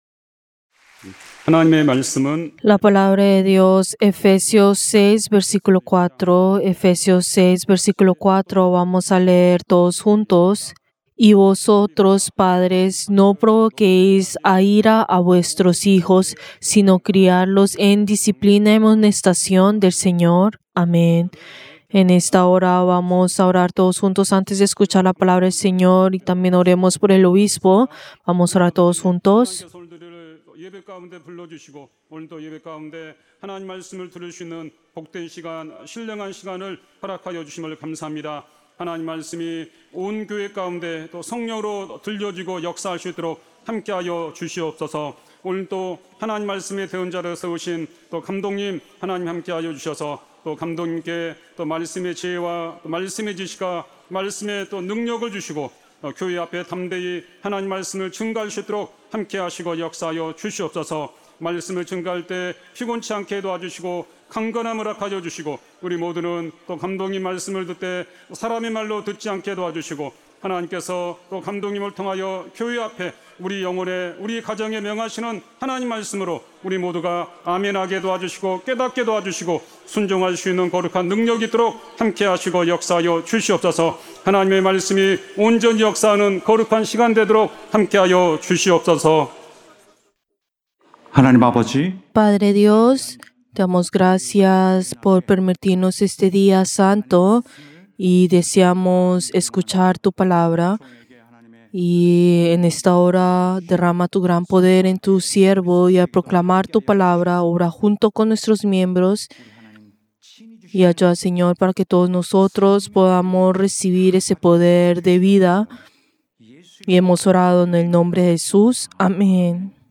Servicio del Día del Señor del 11 de mayo del 2025